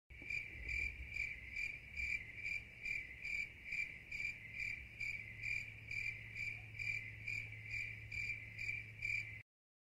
Crickets_awkward.mp3